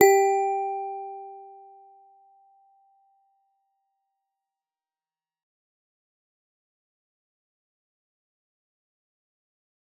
G_Musicbox-G4-f.wav